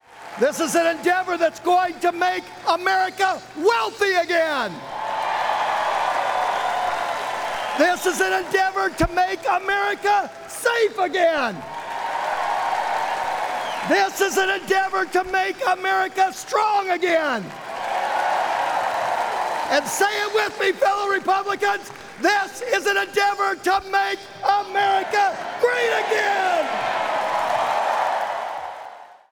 Iowa G-O-P chairman Jeff Kaufmann was chosen to kick off the process with a nominating speech.